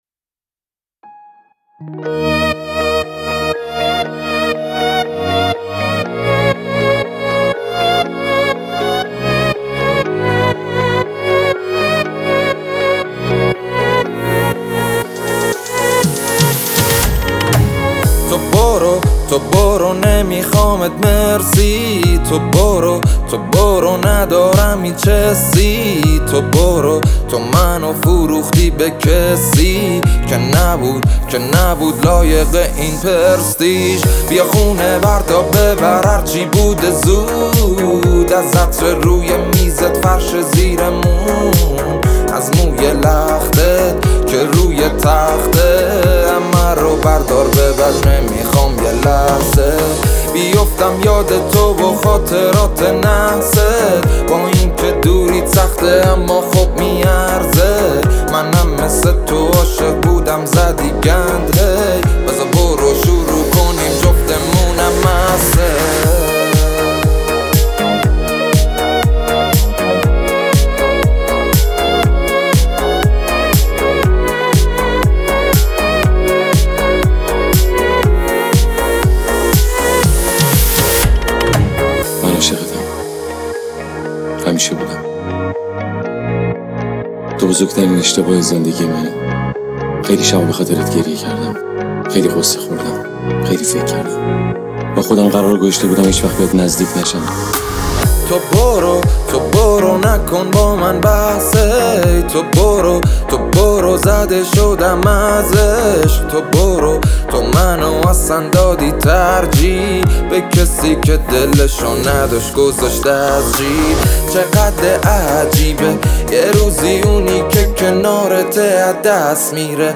پاپ
آهنگ با صدای زن